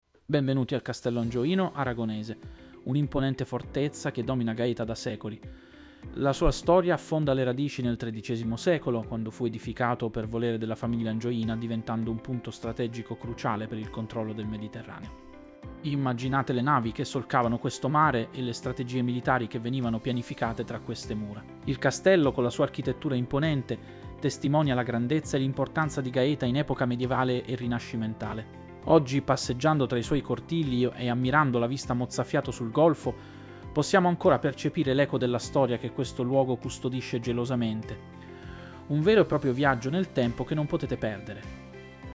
SCOPRI GAETA PODCAST Summary Available Listen to key points in 60 seconds.
Summary Ready Press play to listen 0:00 --:-- AI AI Speaker Legal Notes Join the Scopri Gaeta community!